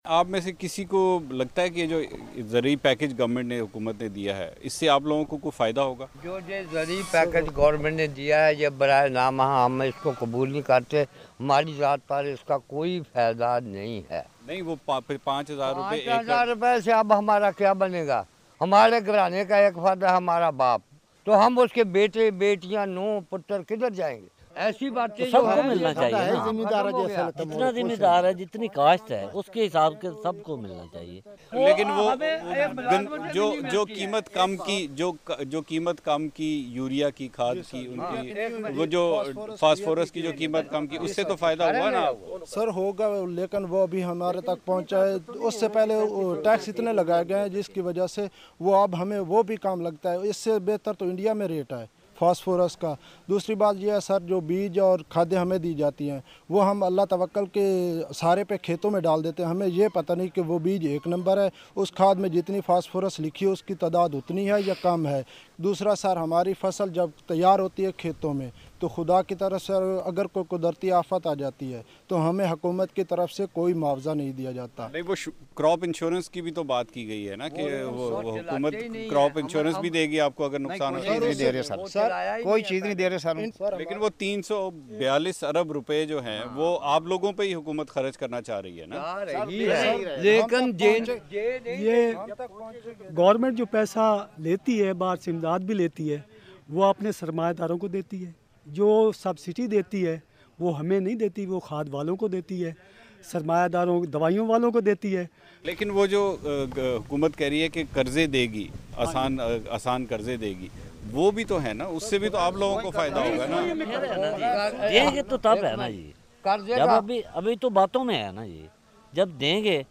صوبہ پنجاب کے ضلع خانیوال میں چند کسانوں سے بات کی۔